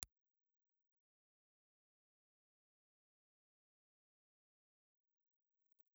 Impulse Response file of a Lustraphone VR65 stereo ribbon microphone (channel 2)
LustraphoneVR65_Stereo2.wav